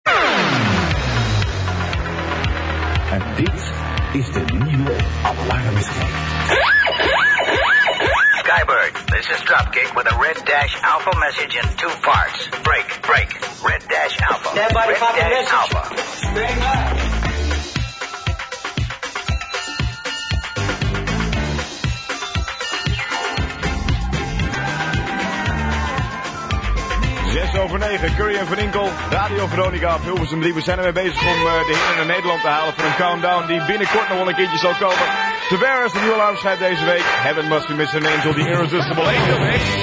Geen minimix vandaag, maar in de uitzending van Curry & van Inkel werd wel de Irrisisteble Angel Mix van Heaven Must Be Missing An Angel uitgezonden.